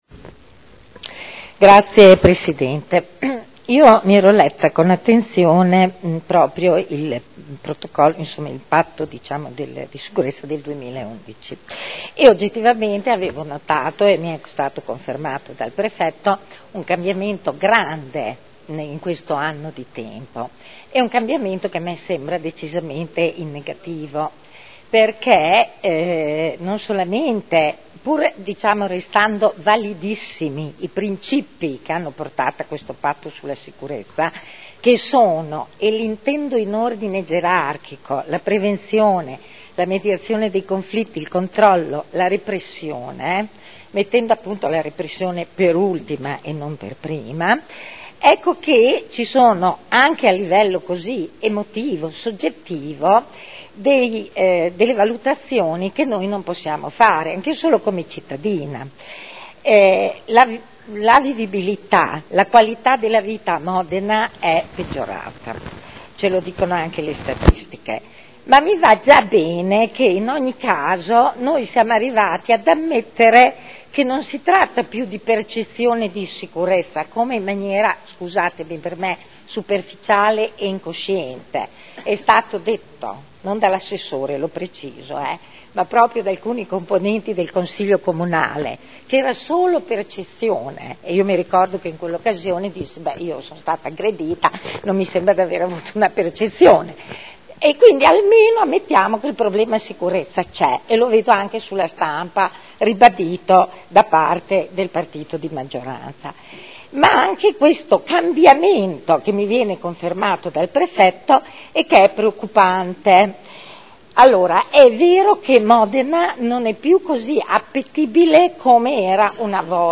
Seduta del 17/05/2012 "Patto per Modena sicura". Dibattito